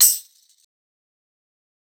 Metro Short Tambourine.wav